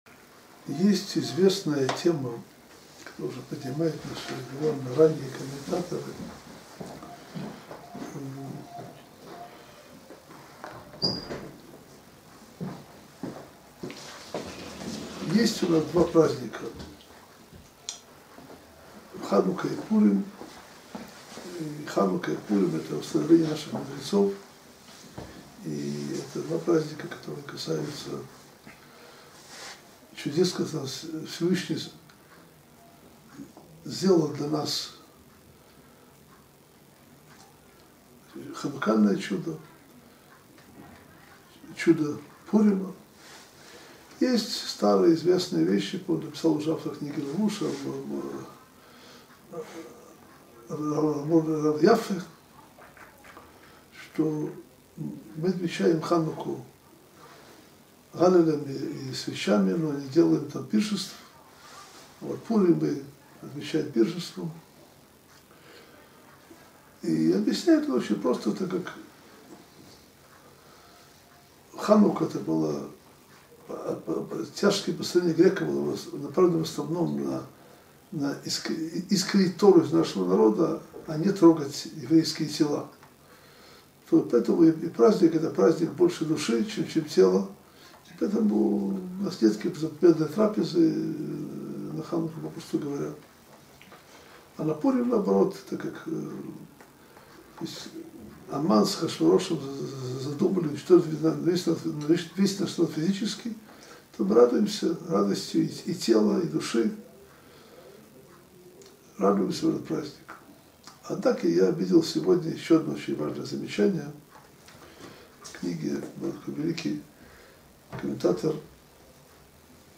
Уроки по Мегилат Эстер